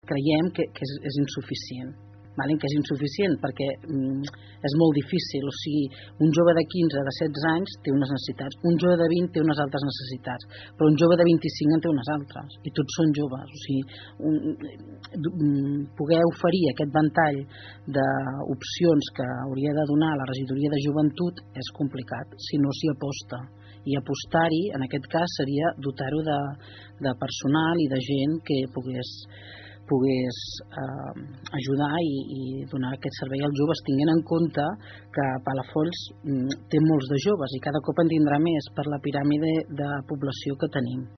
Susanna Pla, regidora d’ERC a l’Ajuntament de Palafolls, va ser dimecres el primer membre de l’oposició al ple de l’Ajuntament que passava pel programa Assumptes Interns.
Durant l’entrevista, la regidora va explicar la posició que manté la seva formació respecte als recursos que l’Ajuntament destina a joventut.